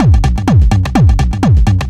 DS 126-BPM A10.wav